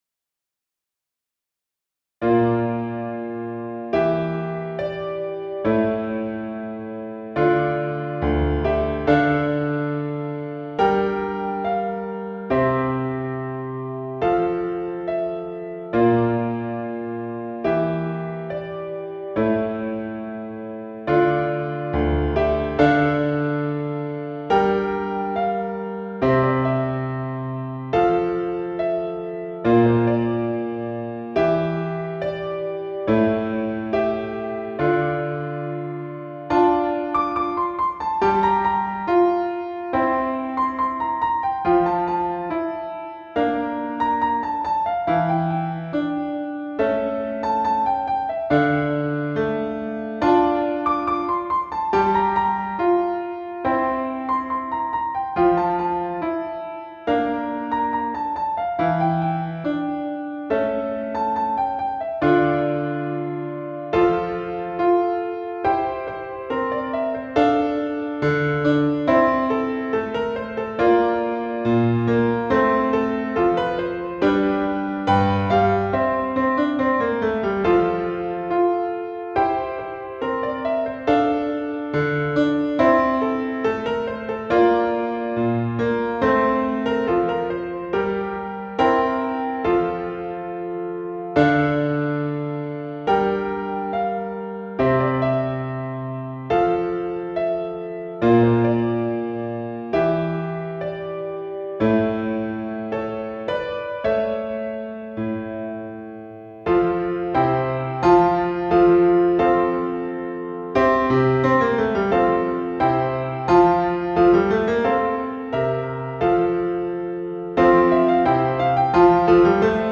TROMBA SOLO • ACCOMPAGNAMENTO PIANO + BASE MP3
Anonimo Trombone